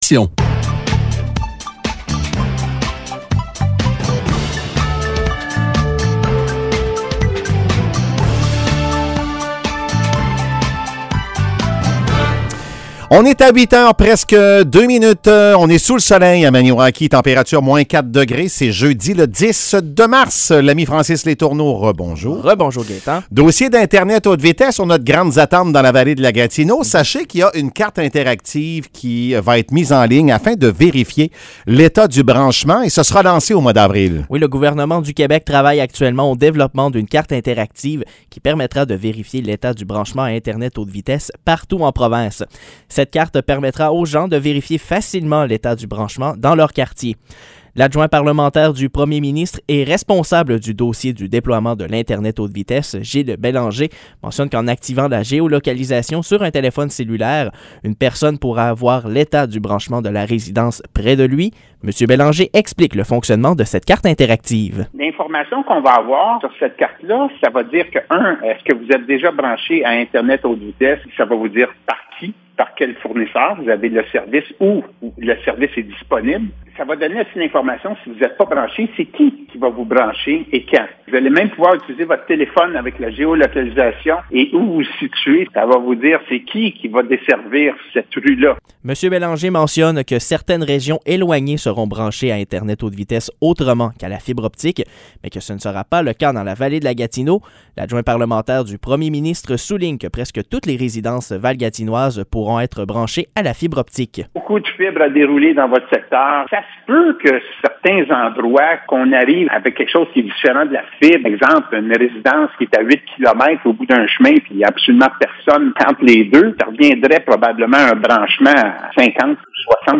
Nouvelles locales - 10 mars 2022 - 8 h